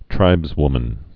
(trībzwmən)